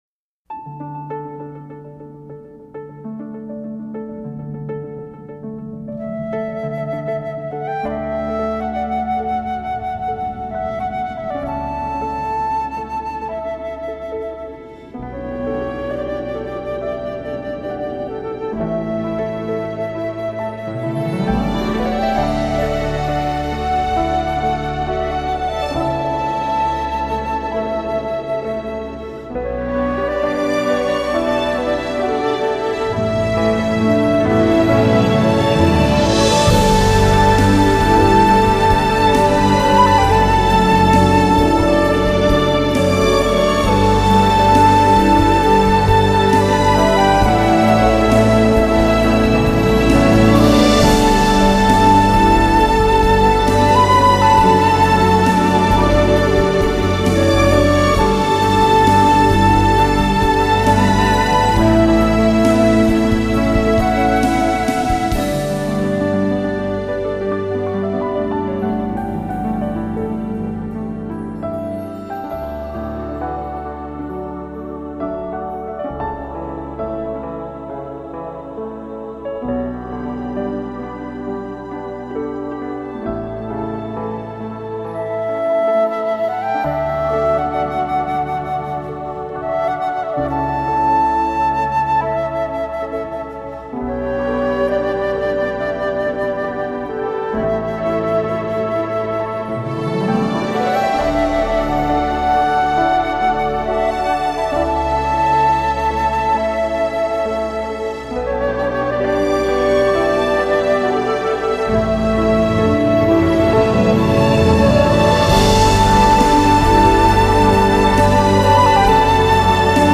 很少使用那些 绚丽复杂的旋律